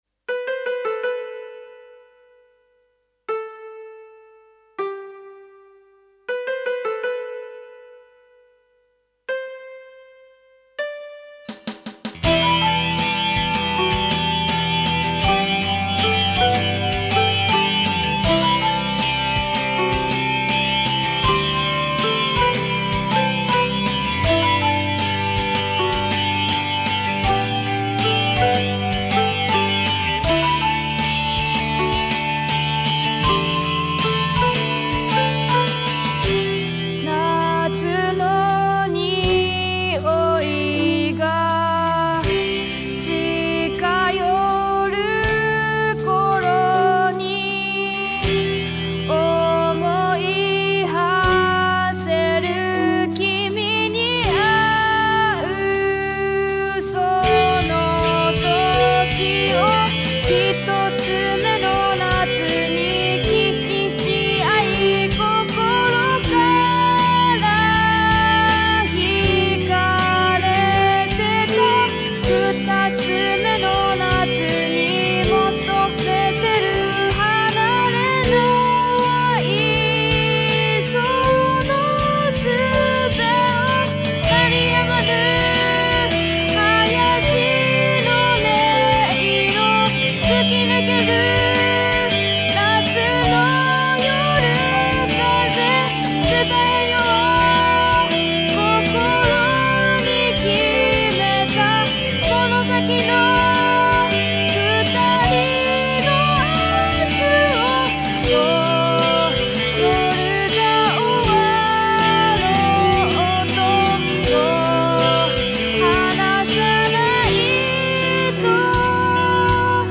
※まだ上手く調整出来ていないので、少し棒読みな歌い方になっています。
一先ずは、人工知能が歌い上げるDark core.に耳を傾けて下さい…。